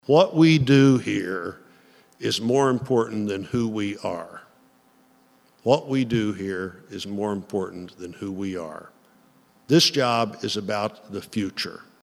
Senator Roy Blunt Addresses Missouri State Representatives
U.S. Senator Roy Blunt addressed Missouri’s state representatives Wednesday morning.